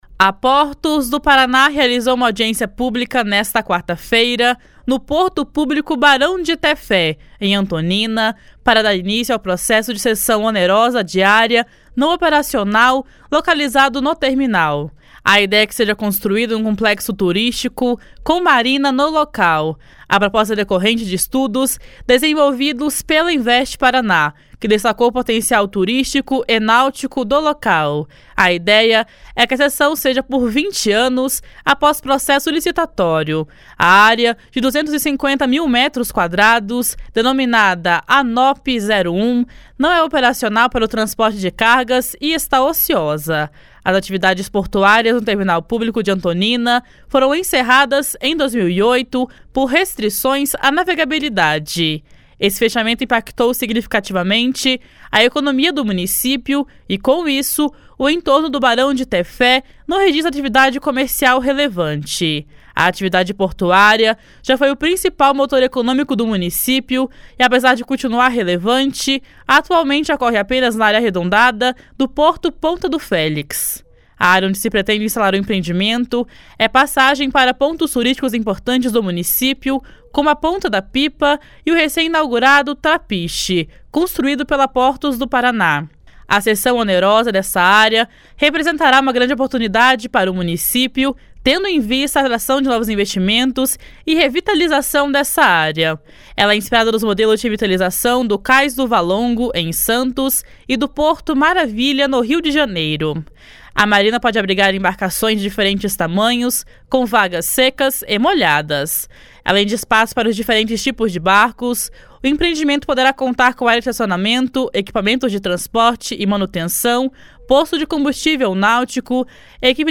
O prefeito de Antonina, José Paulo Vieira Azim, disse que a revitalização da área é de extrema importância para o município. // SONORA JOSÉ PAULO //